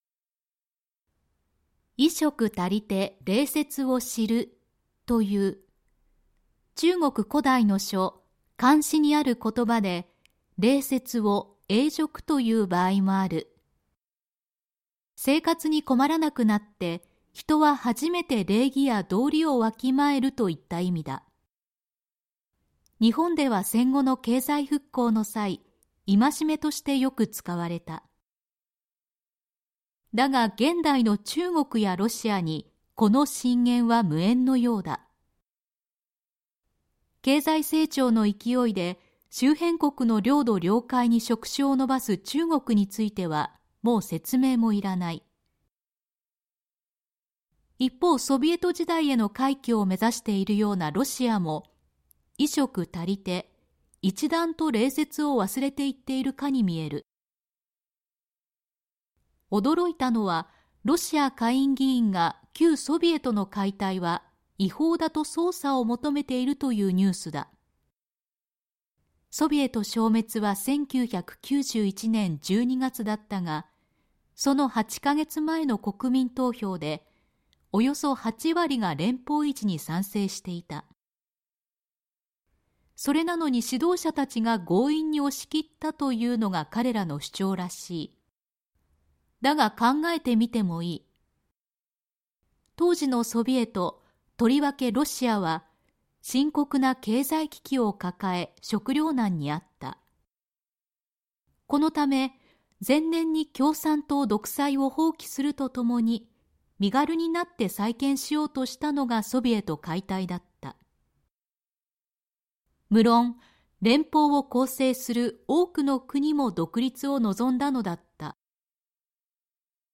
産経新聞1面のコラム「産経抄」を、局アナnetメンバーが毎日音読してお届けします。